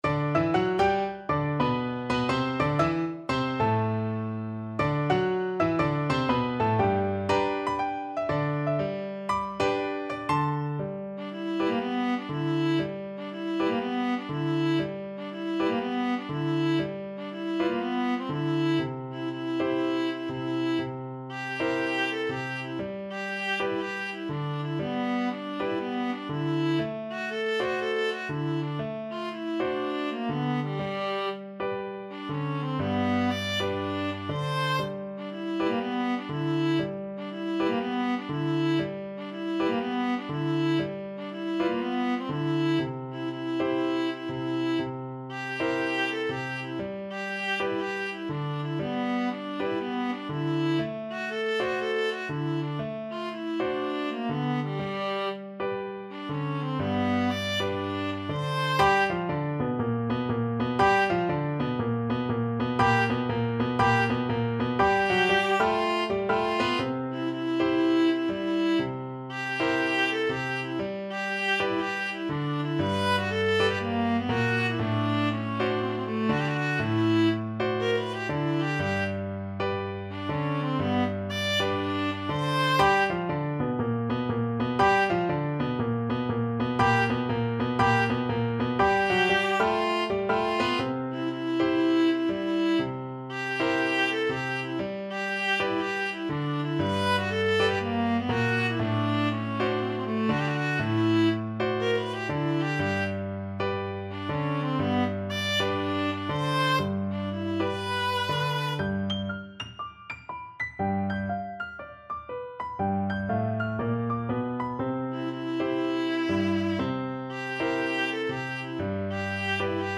Viola
4/4 (View more 4/4 Music)
Moderato = 120
Jazz (View more Jazz Viola Music)